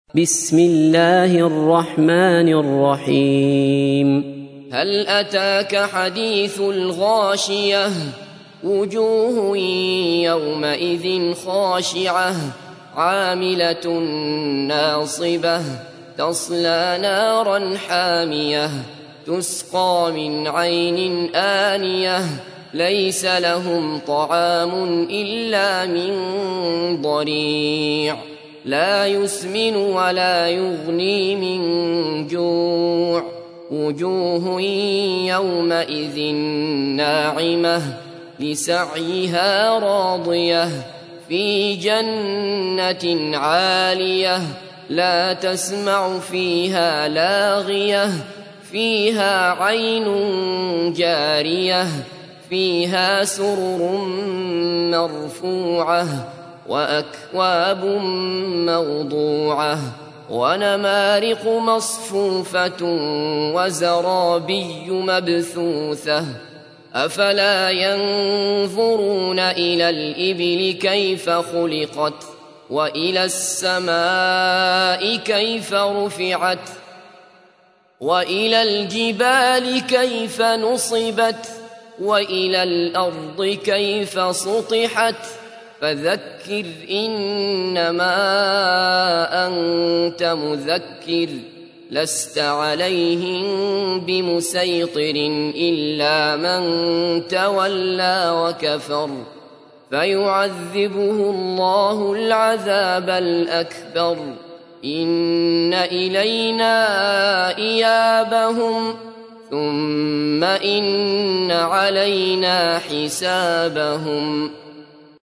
تحميل : 88. سورة الغاشية / القارئ عبد الله بصفر / القرآن الكريم / موقع يا حسين